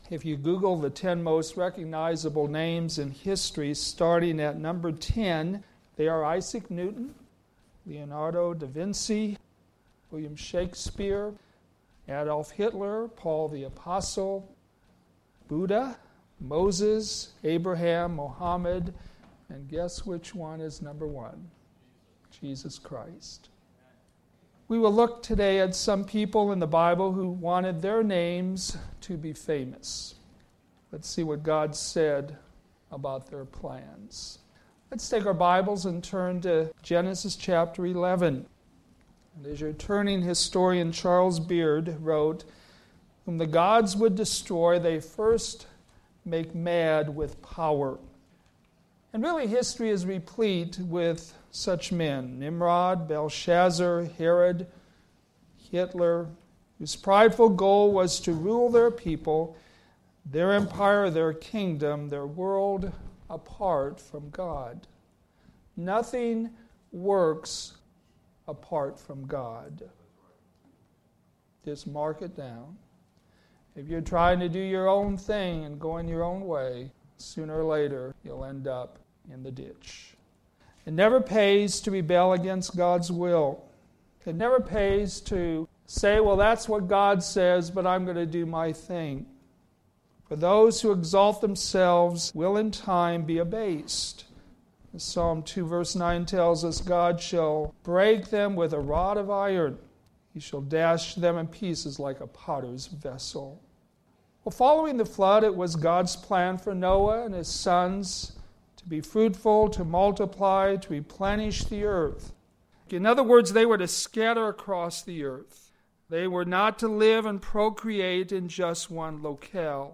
worship Sunday AM